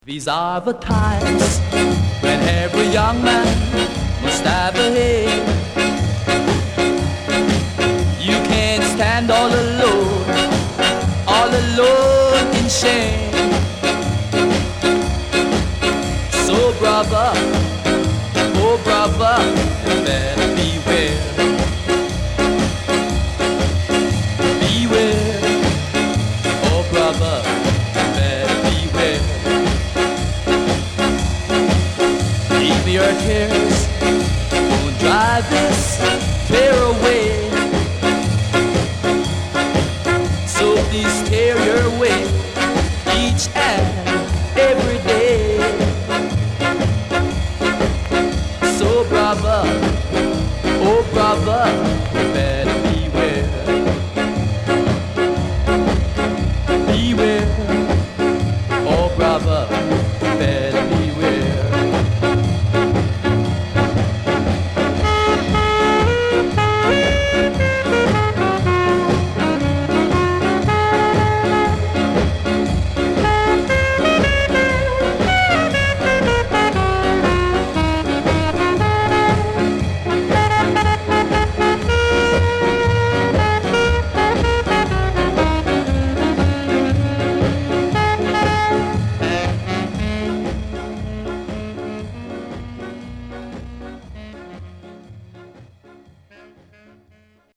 SIDE A:少しチリノイズ、プチノイズ入ります。